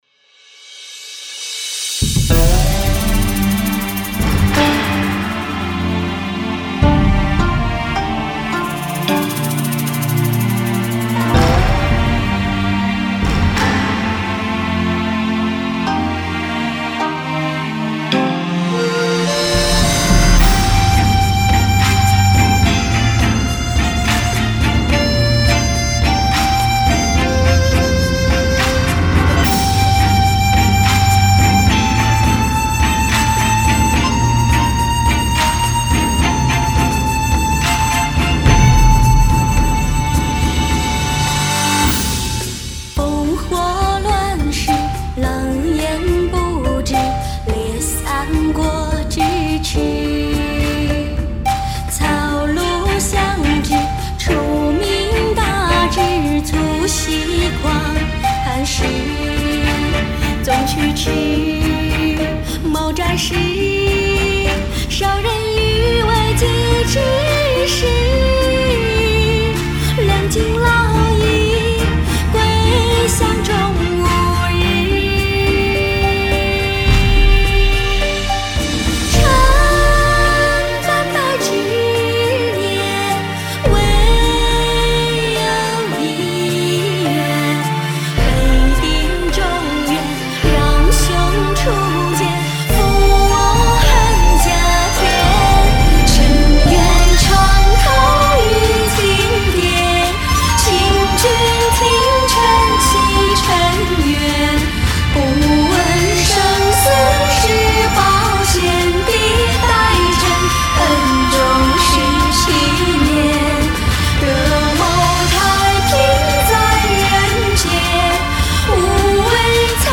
古筝
念白